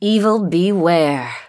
Nekromanta       Naj╢wie┐sze g│osy heros≤w Diablo II. Zapisane w formacie *.wav. Swoj▒ kr≤tk▒ przemowΩ prezentuj▒: Barbarzy±ca, Paladyn, Amazonka, Czarodziejka oraz Nekromanta.
sorceress.mp3